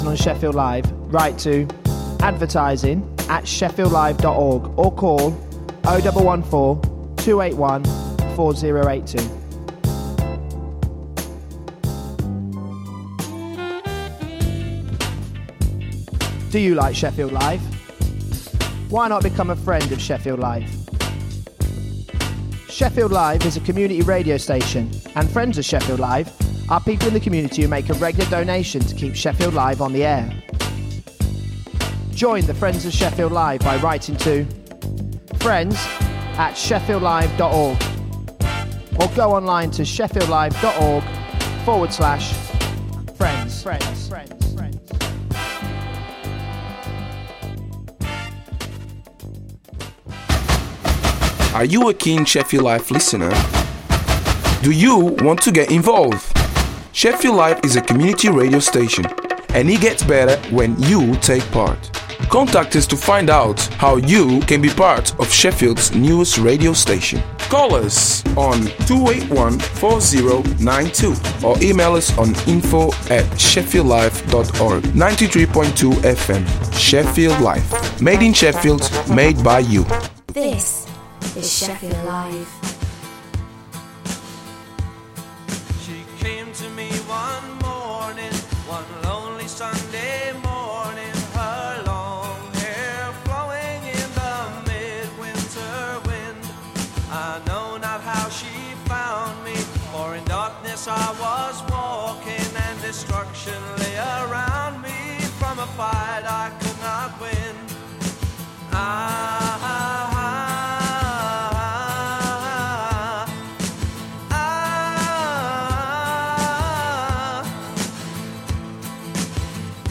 A programme that introduces all different styles of Latin music from its roots and at the same time inform the audience of the latest issues in Latin America, also interviews with artists visiting the city (not necessarily South American).